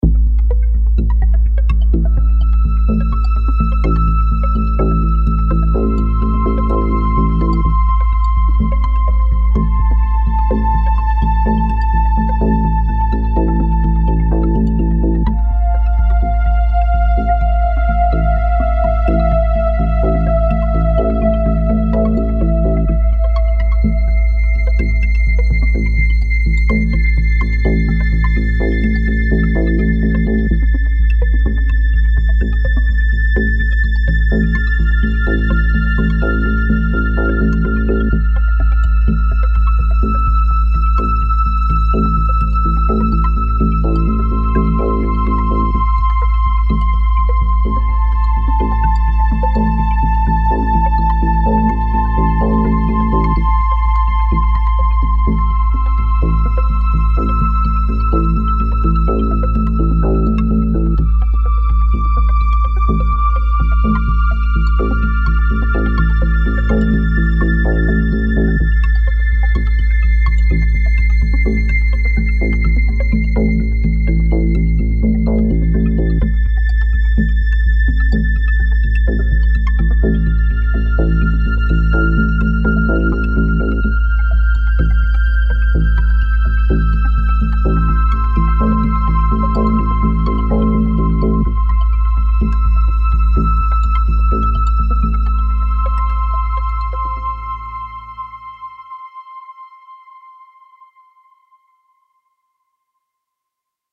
Largo [0-10] - - nappes - aerien - aquatique - drones - ciel